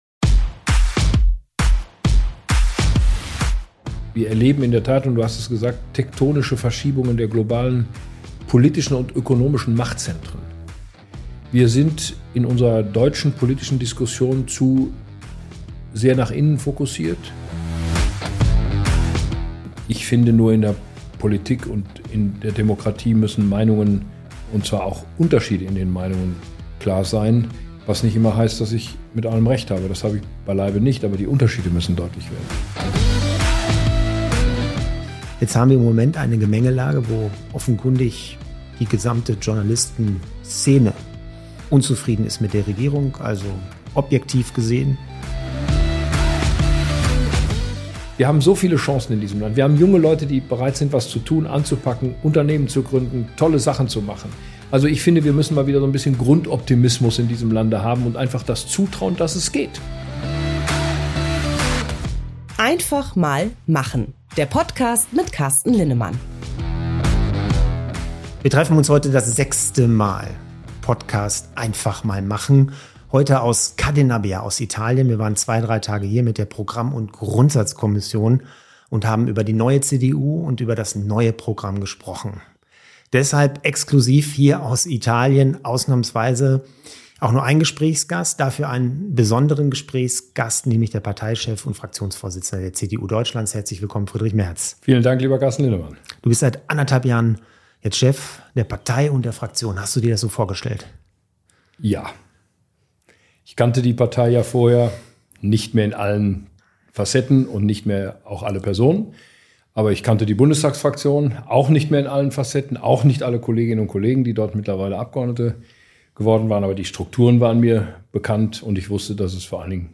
In Konrad Adenauers Villa La Collina am Comer See begrüßt Carsten Linnemann den Vorsitzenden der CDU Deutschlands, Friedrich Merz.